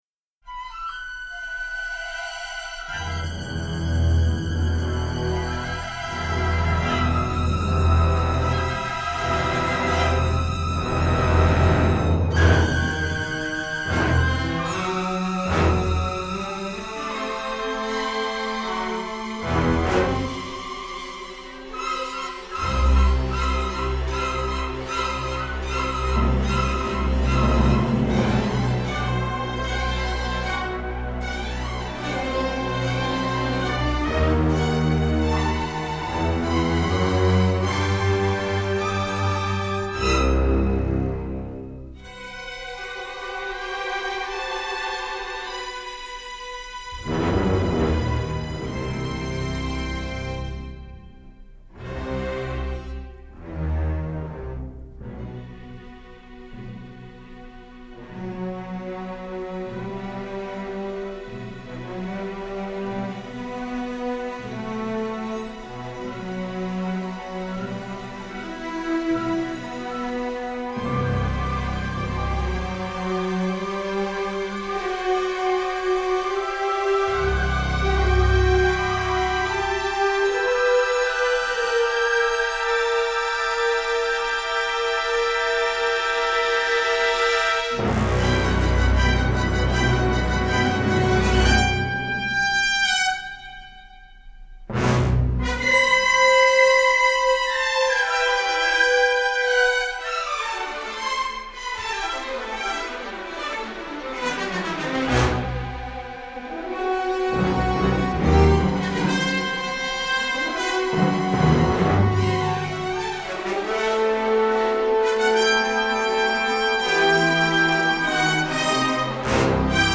Everything has been remastered from superior master elements